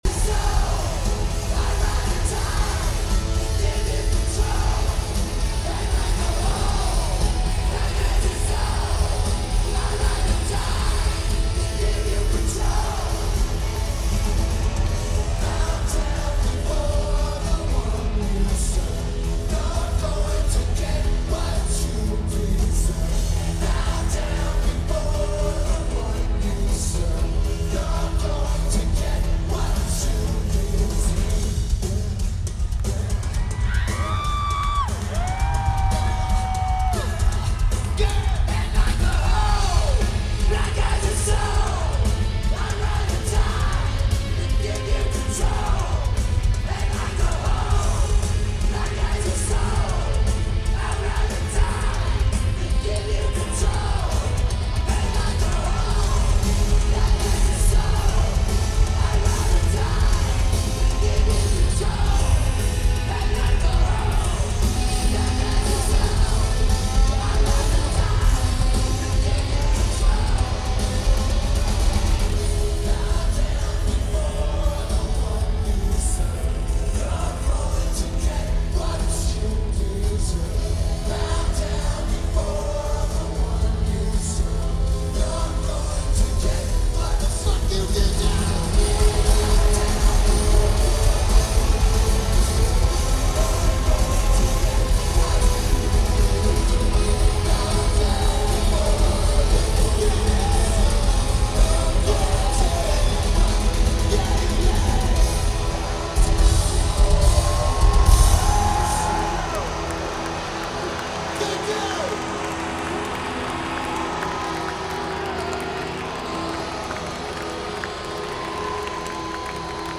Rupp Arena
Drums
Vocals/Guitar/Keyboards
Lineage: Audio - AUD (SP-BMC-3 + SP-BM6 + Sony MZRH-10)